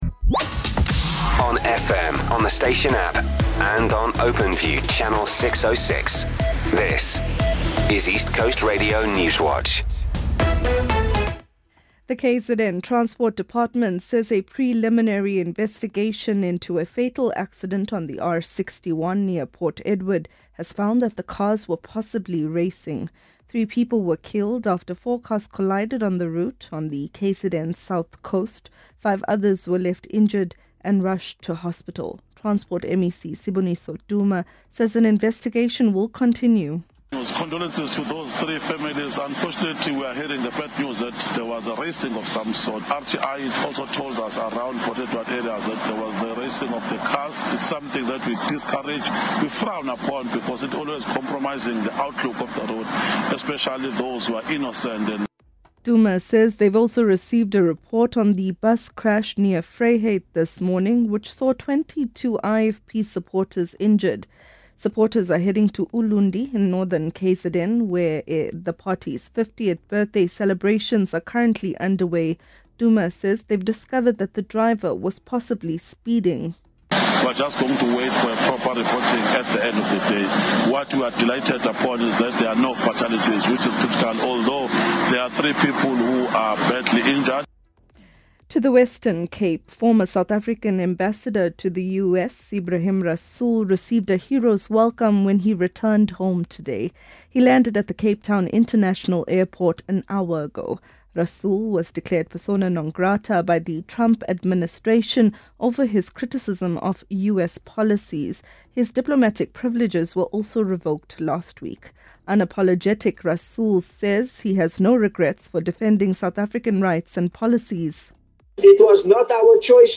East Coast Radio Newswatch is the independent Durban-based radio station's news team. We are KwaZulu-Natal’s trusted news source with a focus on local, breaking news.